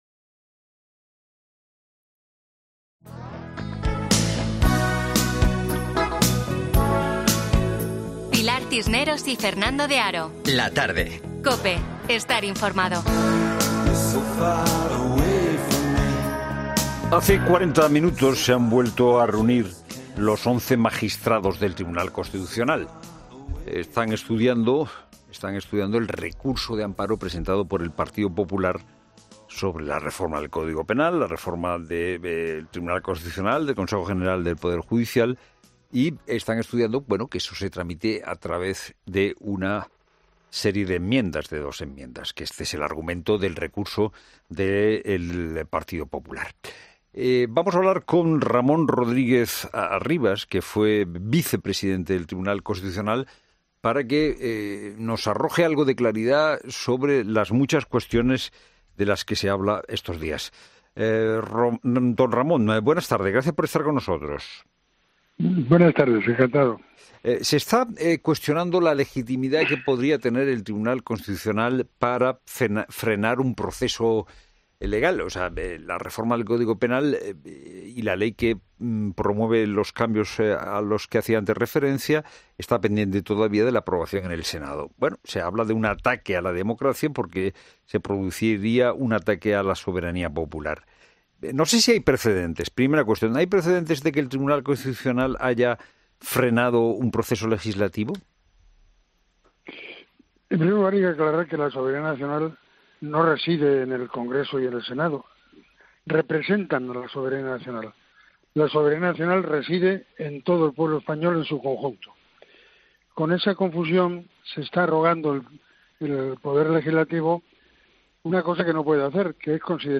Fernando de Haro ha querido reflexionar en 'La Tarde' sobre la legitimidad que podría tener el Tribunal Constitucional para frenar un proceso legal, con la ayuda de Ramón Rodríguez Arribas, exvicepresidente del alto tribunal, y sobre la reforma del código penal que está pendiente todavía de su aprobación en el Senado.